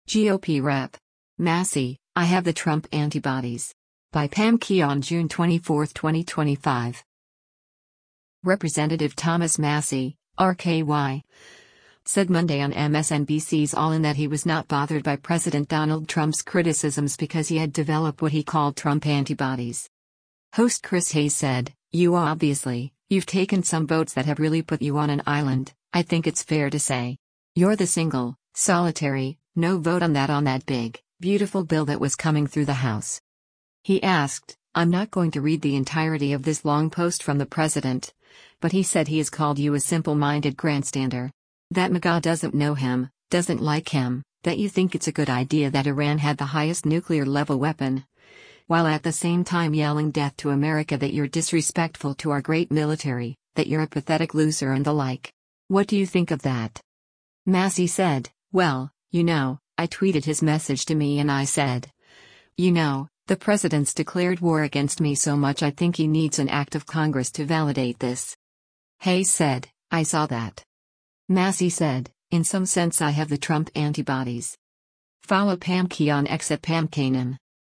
Representative Thomas Massie (R-KY) said Monday on MSNBC’s “All In” that he was not bothered by President Donald Trump’s criticisms because he had developed what he called “Trump antibodies.”